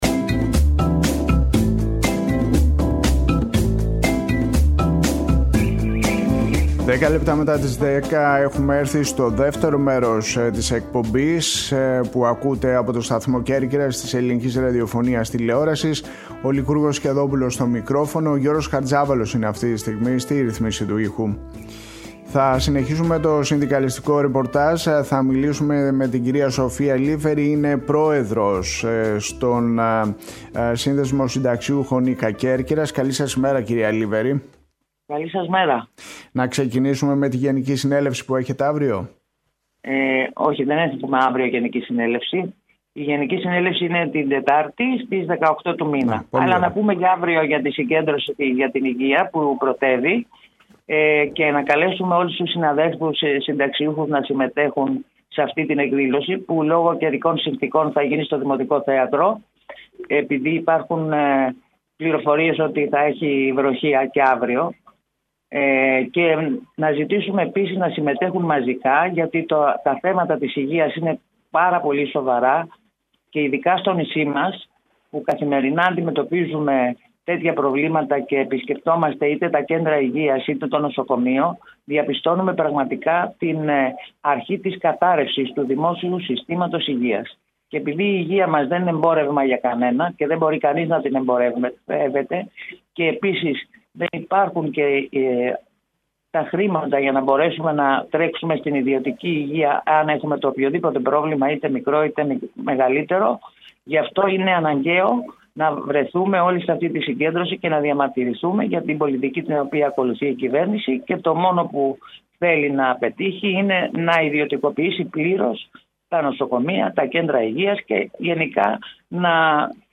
Τέλος, ακούγονται ηχογραφημένα αποσπάσματα από τις συναντήσεις που είχε με τοπικούς φορείς η Υπουργός Τουρισμού Όλγα Κεφαλογιάννη. «Εννέα στην ΕΡΤ» Οριοθέτηση της ειδησιογραφίας στην Κέρκυρα, την Ελλάδα και τον κόσμο, με συνεντεύξεις, ανταποκρίσεις και ρεπορτάζ.